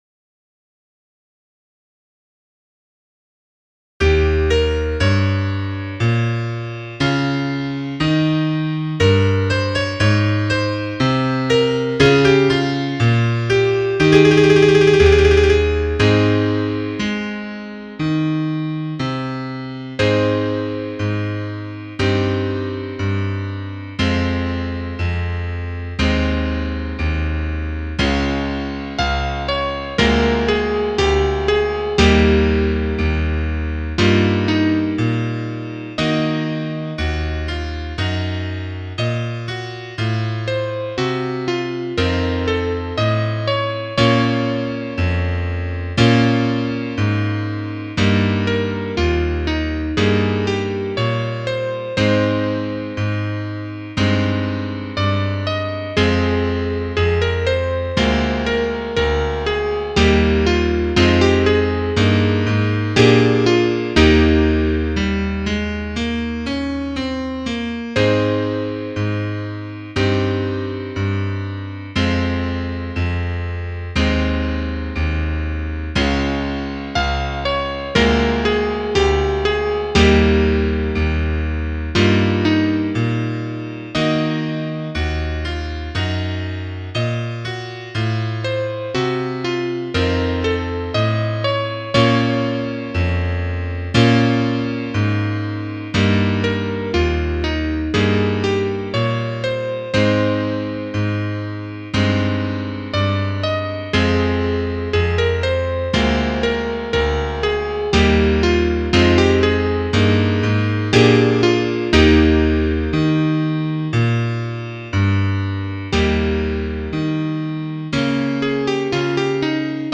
Trompete 4